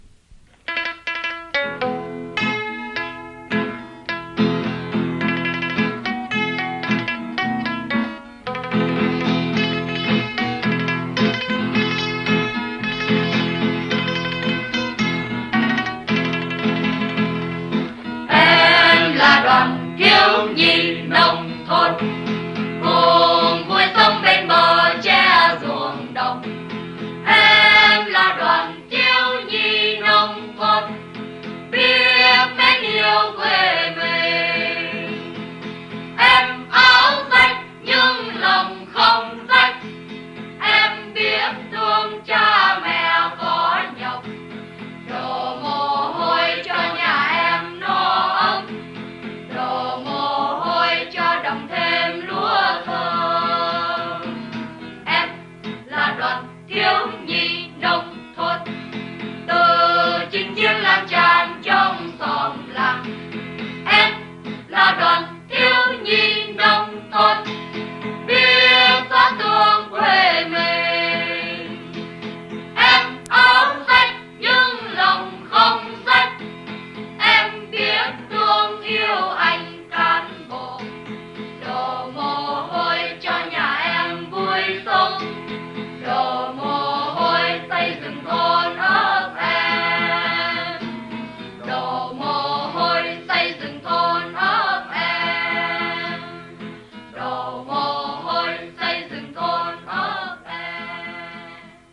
Nhạc sinh hoạt Thiếu nhi XDNT
hợp xướng